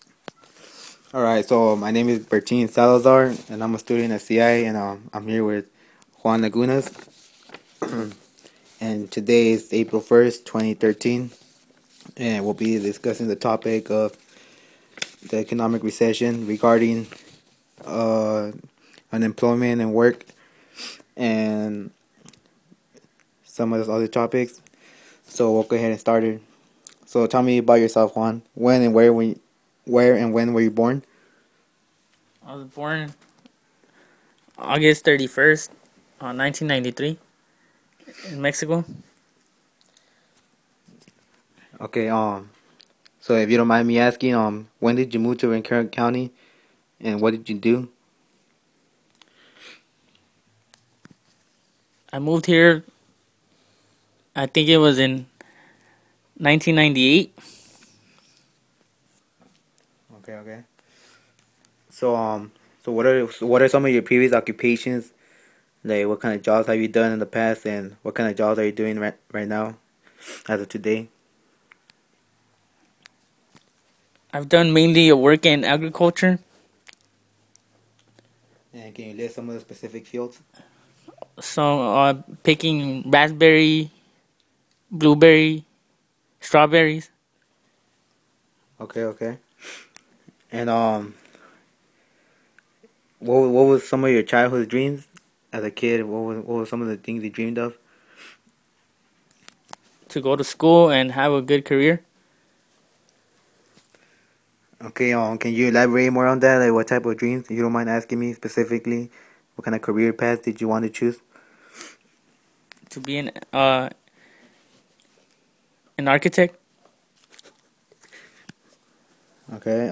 • Oral history